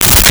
Space Gun 15
Space Gun 15.wav